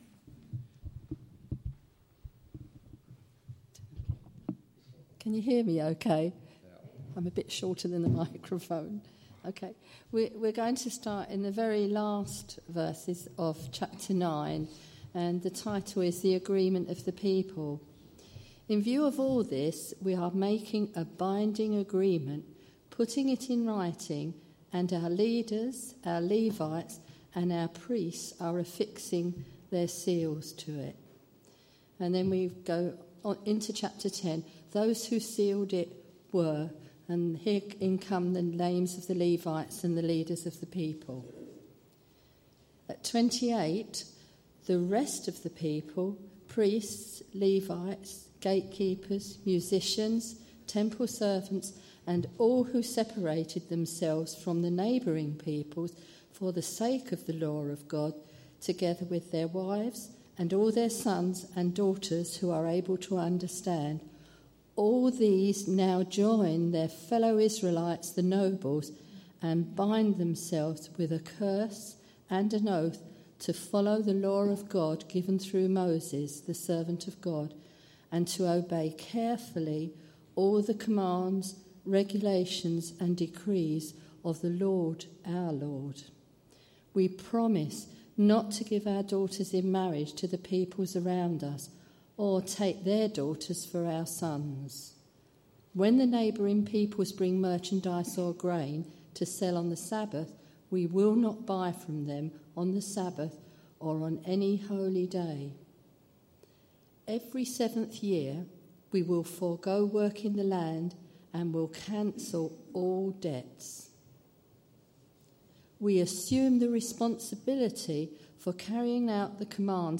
A message from the series "Nehemiah."